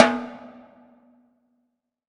WTIMBALE L1R.wav